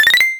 badge-dink-max.wav